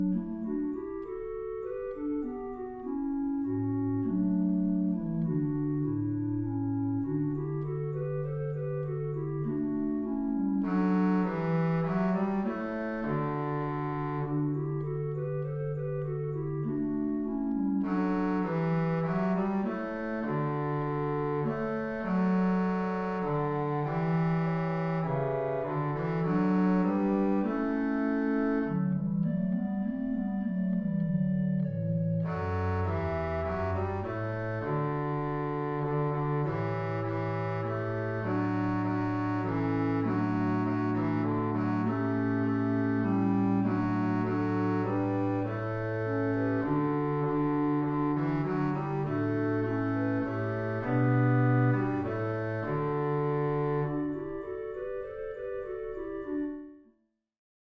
Voicing: SA Men, upper/lower, accompanied or unaccompanied